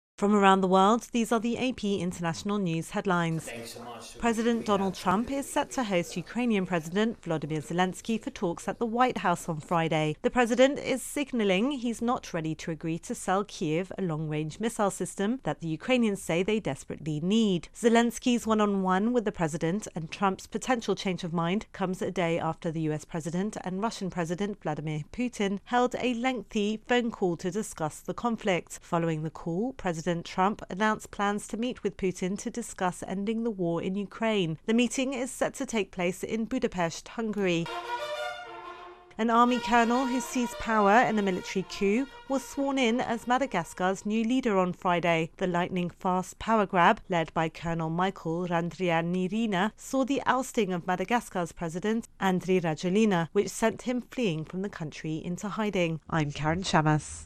The latest international news headlines